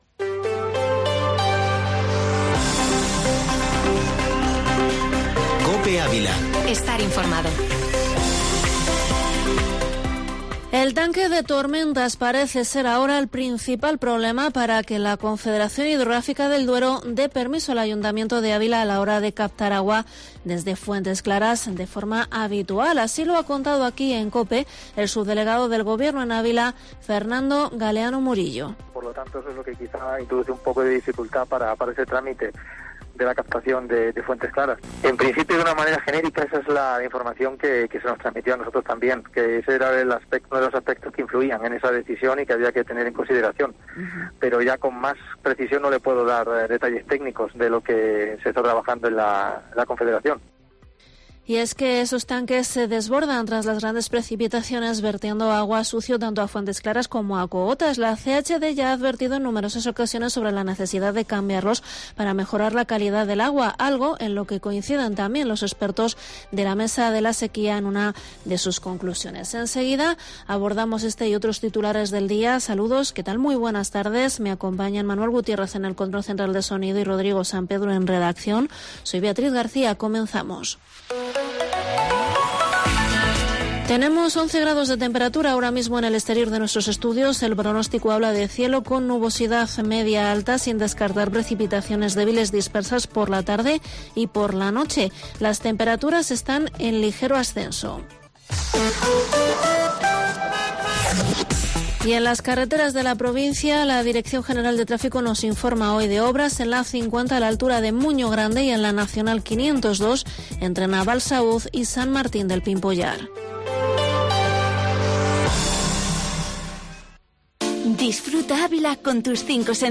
Informativo Mediodía Cope en Avila 28/3/22